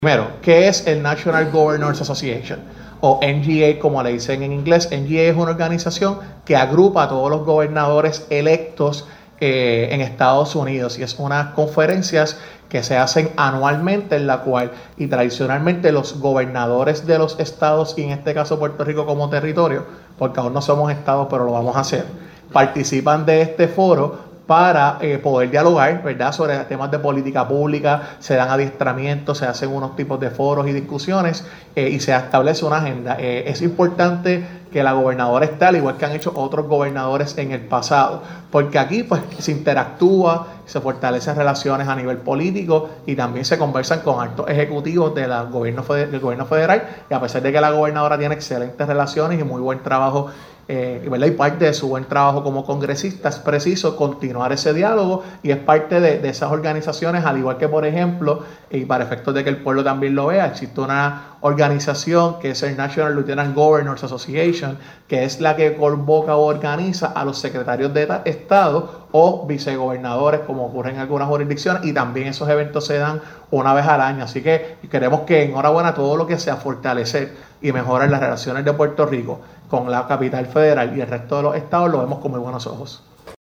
“Busca impulsar el financiamiento de programas federales, impulsar la reconstrucción de la isla y la transformación de la red eléctrica”, dice el titular de Asuntos Públicos sobre el viaje de la gobernadora (sonido)